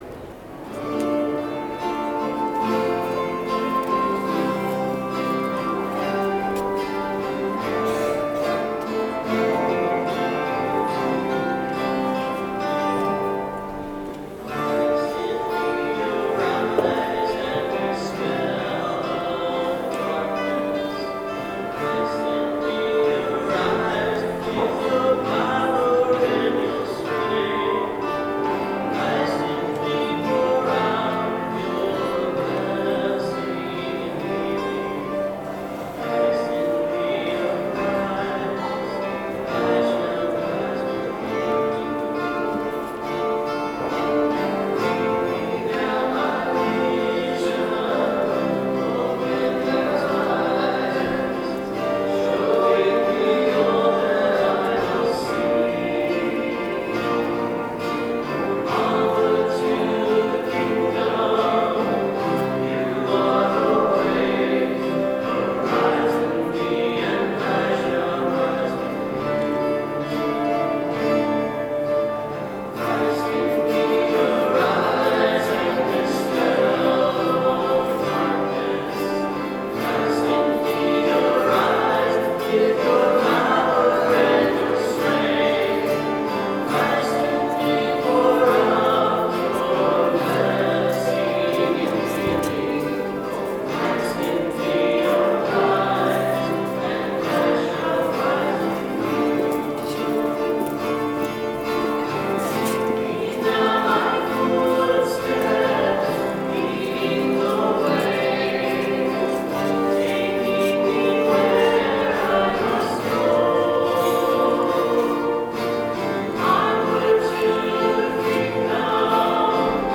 Music from the 10:30 Mass on the 1st Sunday in Lent, March 3, 2013: